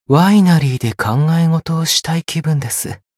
觉醒语音 ワイナリーで考え事をしたい気分です 媒体文件:missionchara_voice_448.mp3